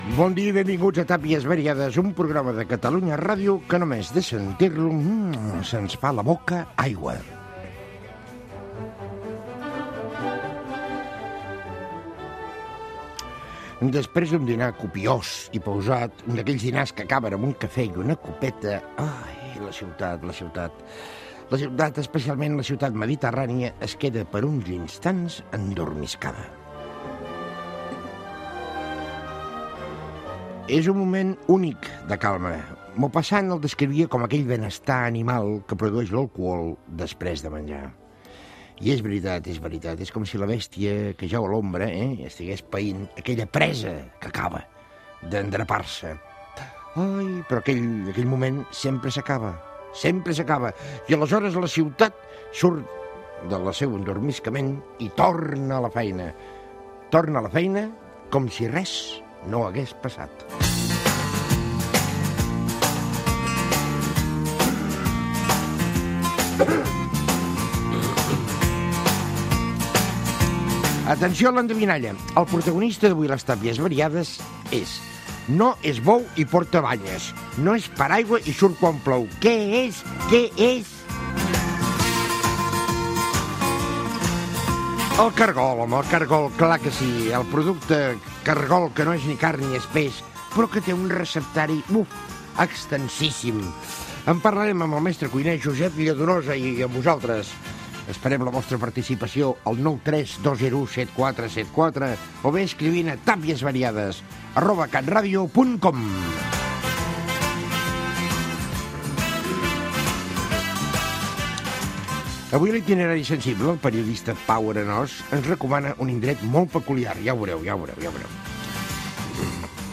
Benvinguda, la migdiada de la ciutat, endevinalla, formes de contactar amb el programa, sumari de continguts, indicatiu de l'emissora, publicitat, indicatiu de l'emissora, les cargolades, la fitxa del cargol
Divulgació